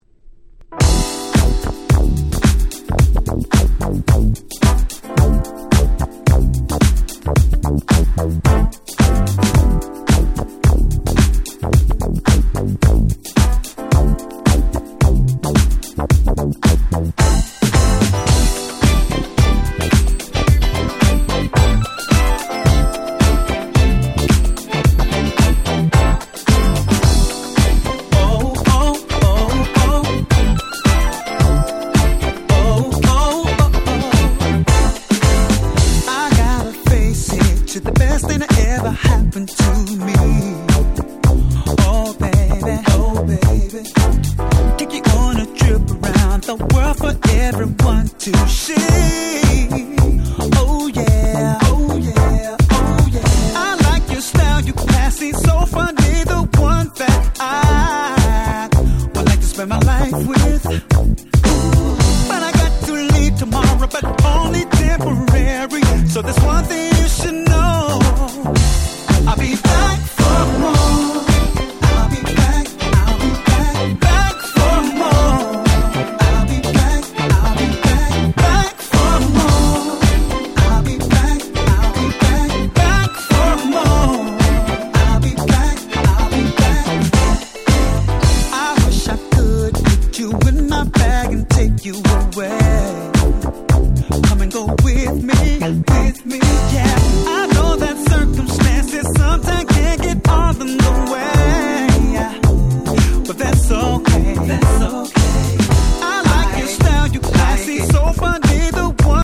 10' Very Nice R&B / Disco / Boogie !!